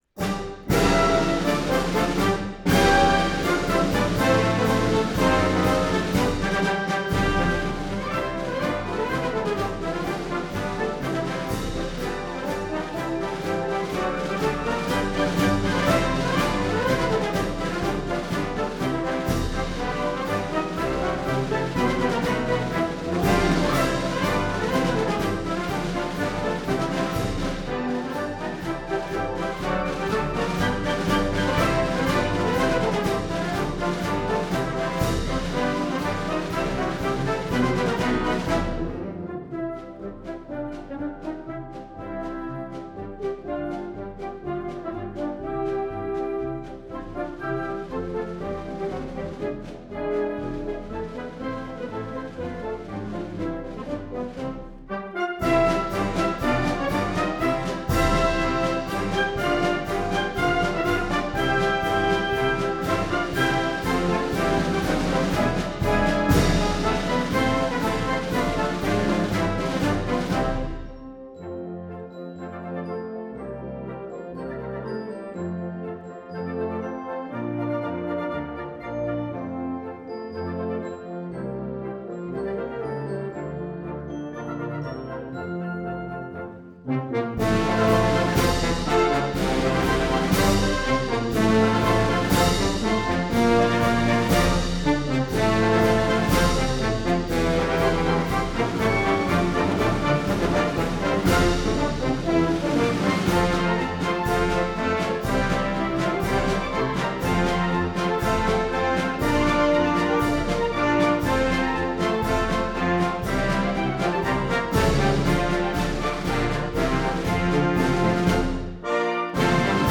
Ancient and Honorable Artillery Company March from The Complete Marches of John Philip Sousa: Vol. 6